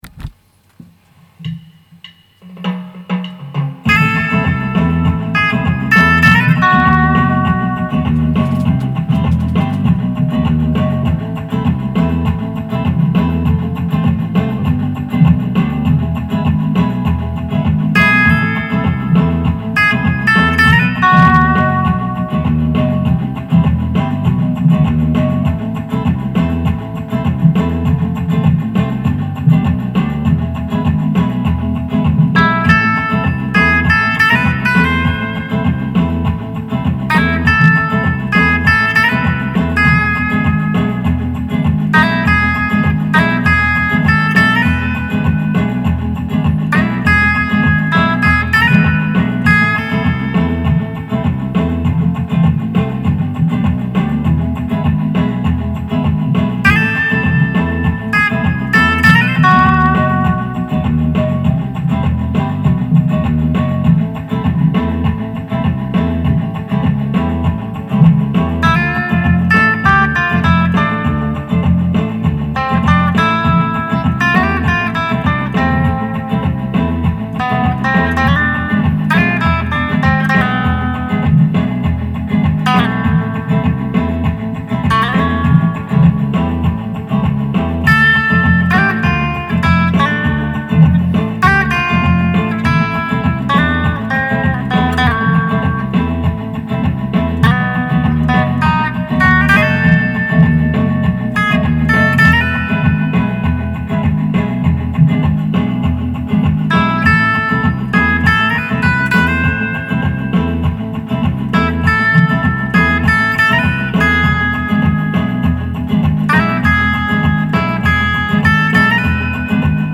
Pop Flamenco